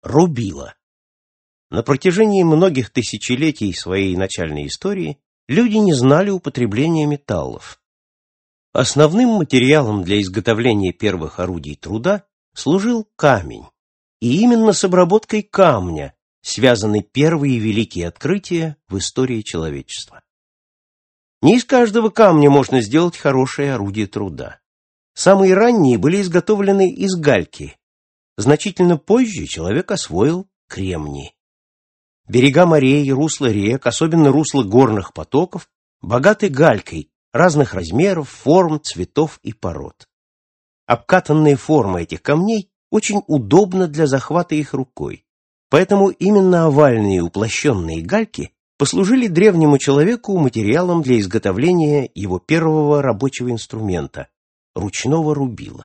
Аудиокнига Энциклопедия юного изобретателя | Библиотека аудиокниг